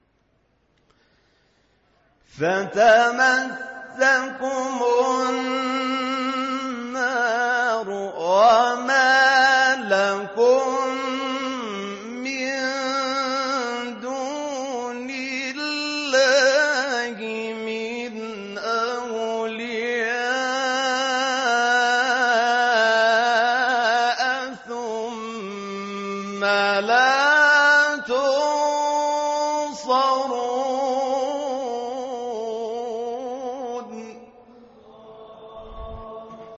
گروه شبکه اجتماعی: فرازهای صوتی از قاریان ممتاز و تعدادی از قاریان بین‌المللی کشورمان را می‌شنوید.